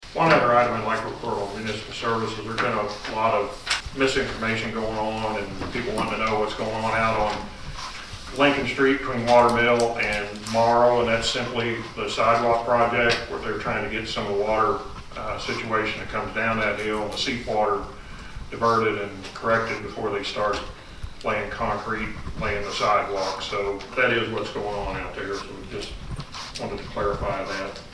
Marshall Ward 3 Councilman and Mayor Pro Tem Dan Brandt gave some information involving some work taking place on city-owned property on South Lincoln Avenue.
During the city council meeting on Monday, May 17, Brandt said he wants to clear up some misinformation about what’s going on at the Gieringer Property.